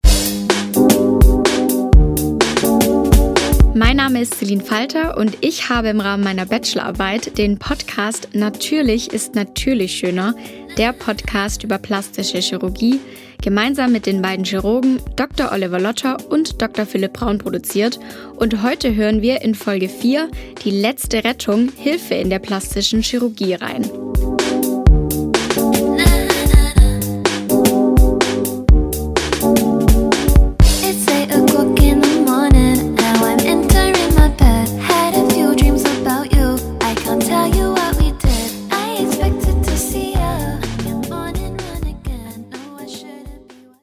Interviewpartner
Teaser_606.mp3